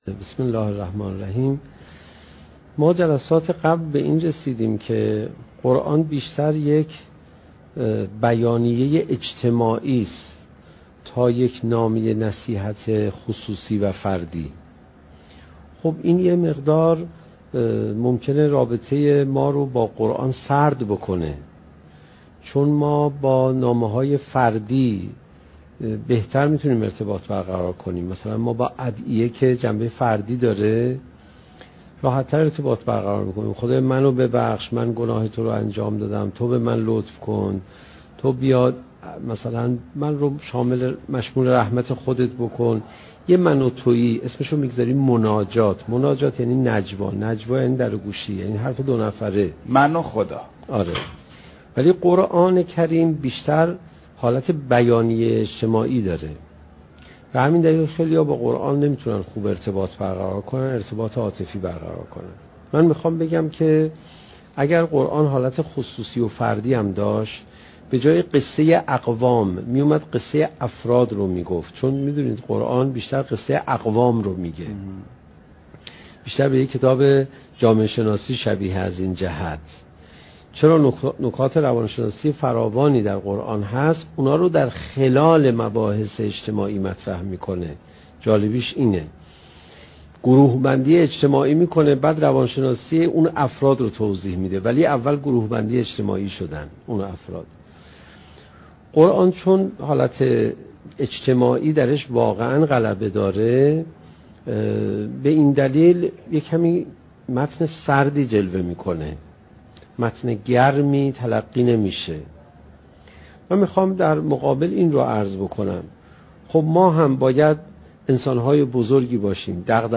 سخنرانی حجت الاسلام علیرضا پناهیان با موضوع "چگونه بهتر قرآن بخوانیم؟"؛ جلسه بیست و ششم: "اثر دغدغه‌های اجتماعی"